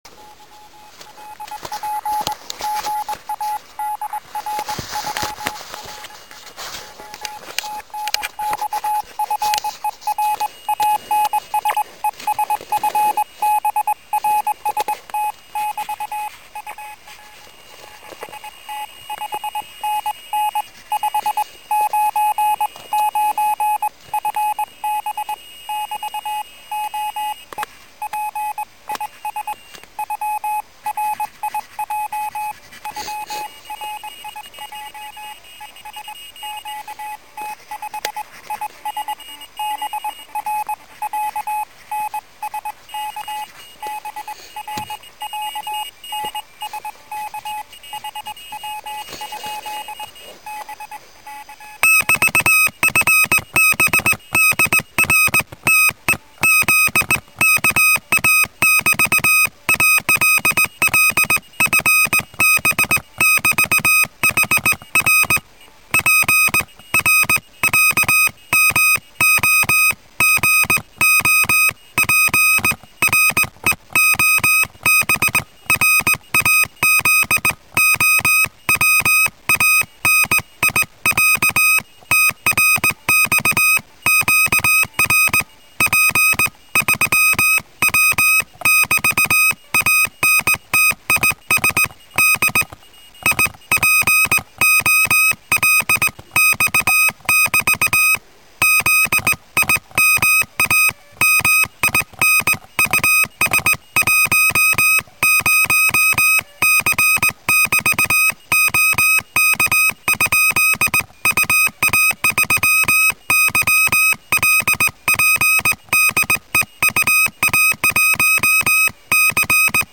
QRP/p.
Ещё запись QSO. Самоконтроль мультивибратором. Частота под воздействием мороза у мультивибратора ушла в верх, да и стал подплакивать.